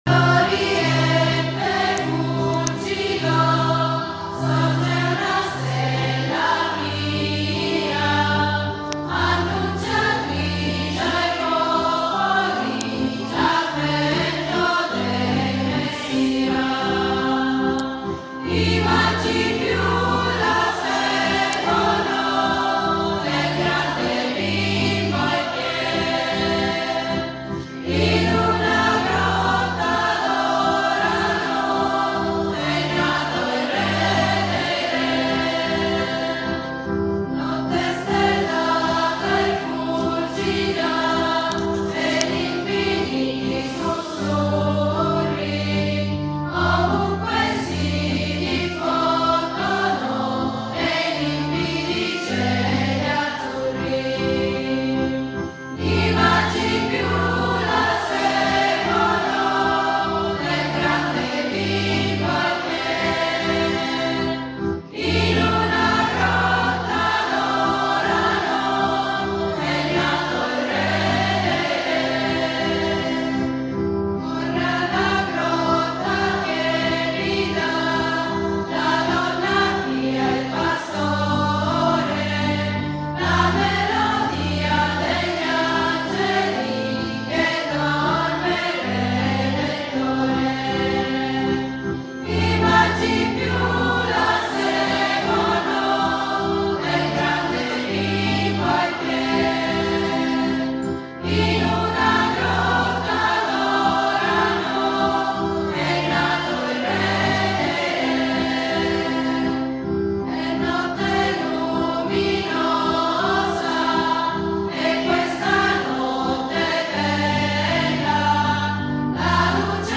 Il coro parrocchiale annuncia la nascita del Messia durante la messa del giorno di Natale